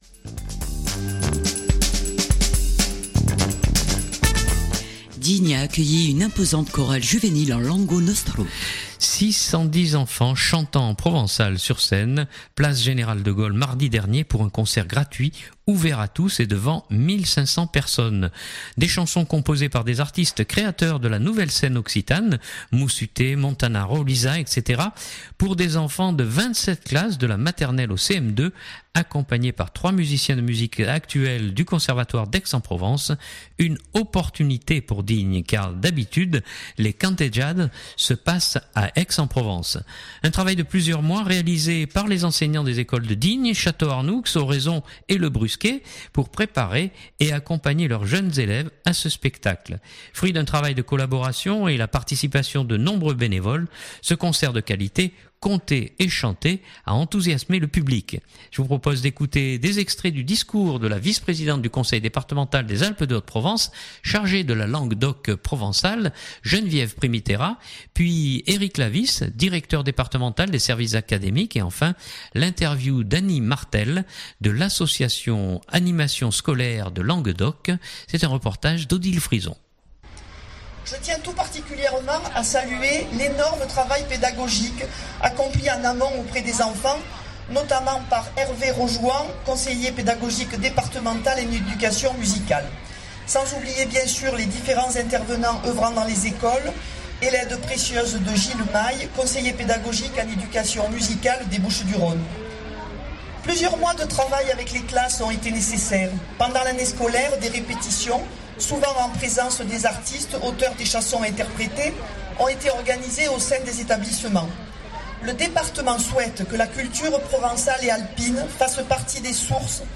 Six cent dix enfants chantant en provençal sur scène, place Général de Gaulle mardi dernier pour un concert gratuit ouvert à tous et devant 1500 personnes.